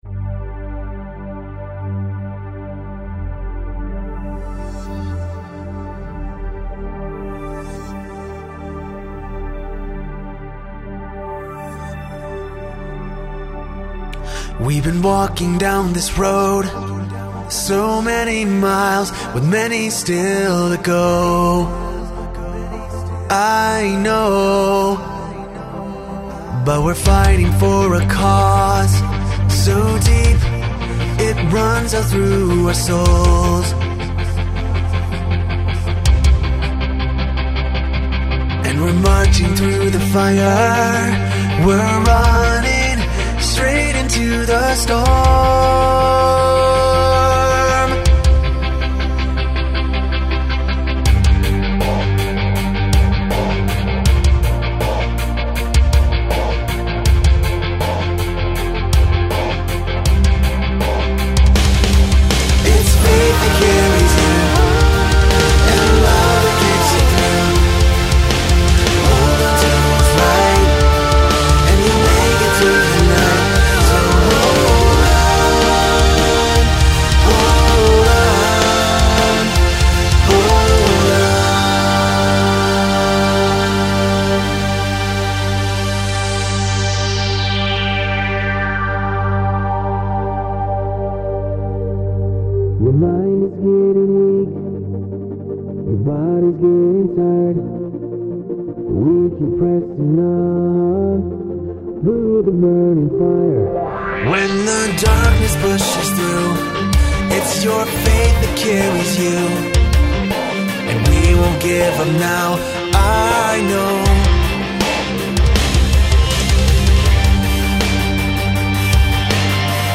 It's christian music themed but hopefully it doesn't sound like every other song on the radio lol. Keep in mind that most of it isn't eq'd, parts haven't been recorded, levels aren't balanced, frequencies may hurt your ears, and it would take some work to get it up to standard but I thought it would be fun to post it here!